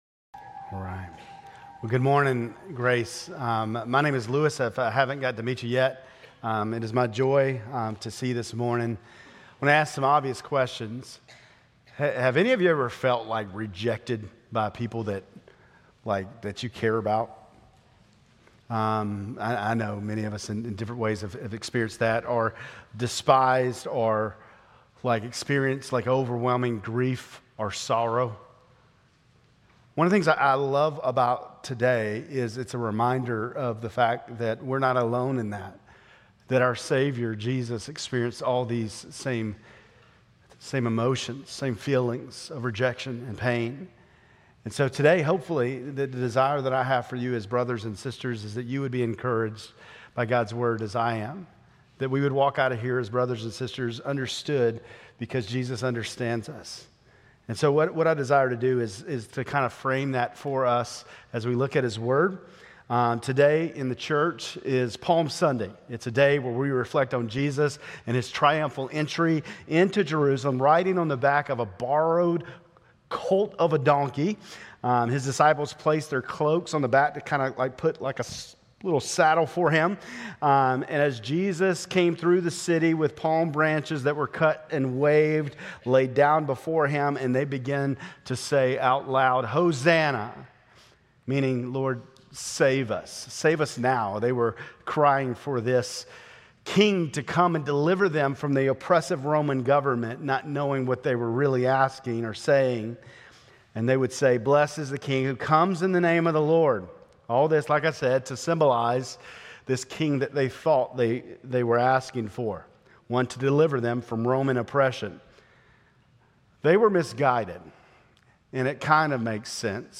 Grace Community Church Lindale Campus Sermons 4_13 Lindale Campus Apr 14 2025 | 00:25:30 1x 00:00 / 00:25:30 Subscribe Share RSS Feed Share Link Embed